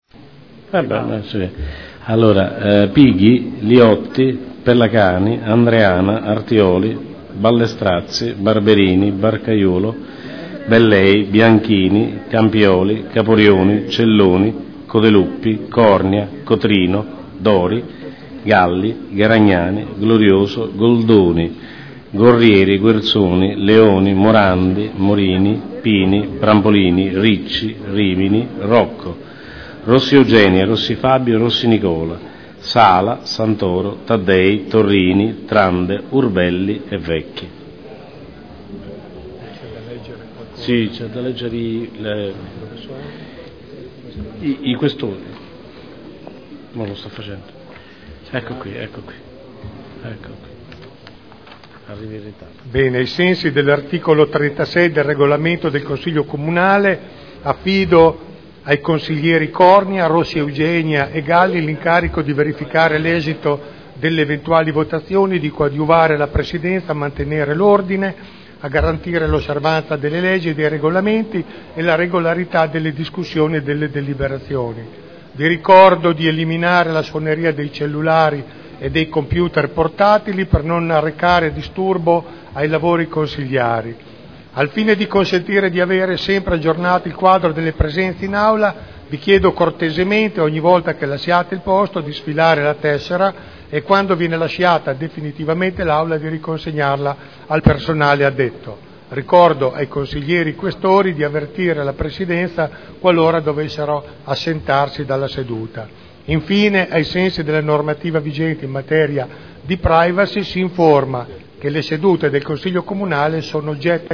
Seduta del 14/03/2011. Appello e apertura Consiglio.